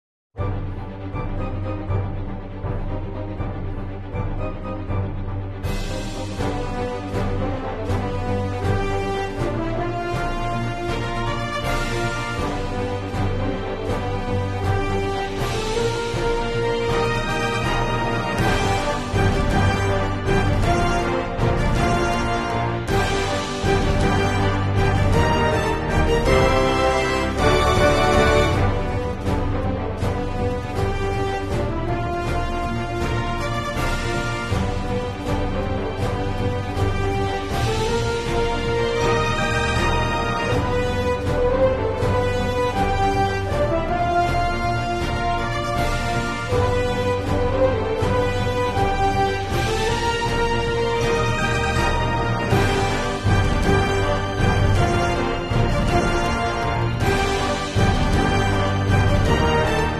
Cutting asmr of the marvel sound effects free download
Cutting asmr of the marvel infinity stones Part 1 ✨ satisfying asmrsounds for relaxation and sleep